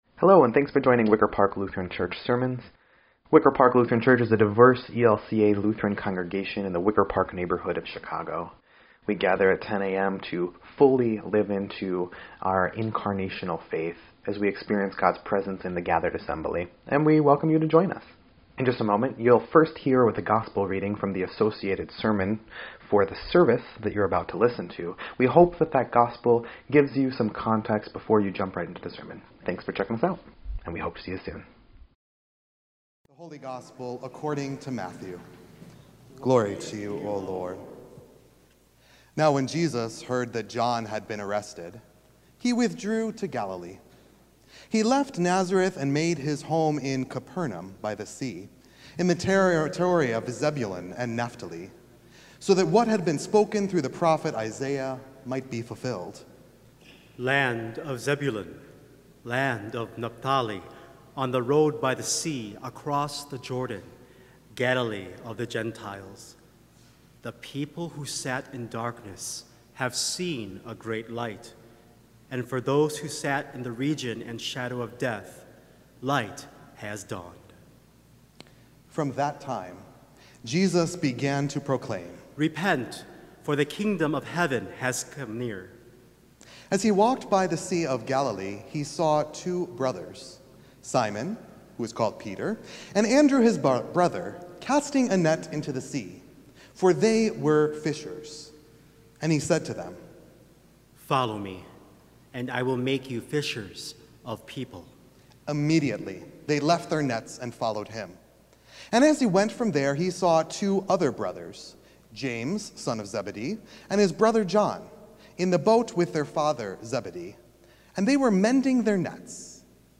1.25.26-Sermon_EDIT.mp3